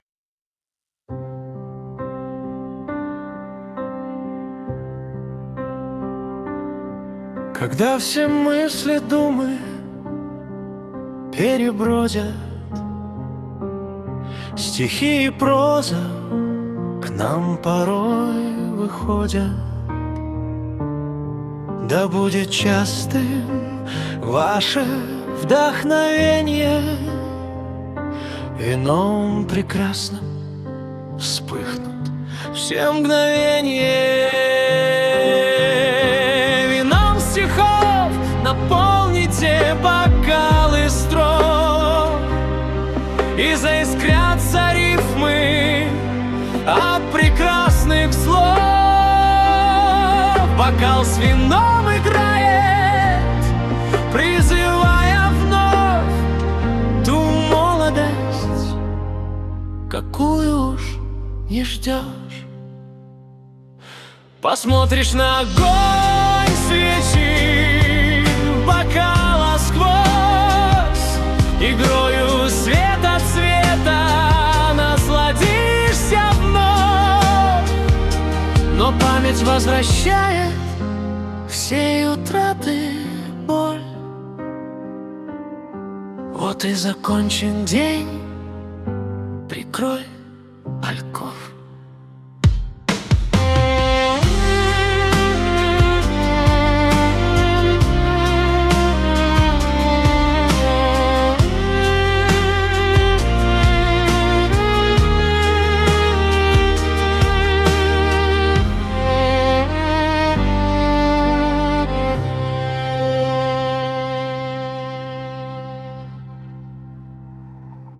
• Статья: Лирика
Романс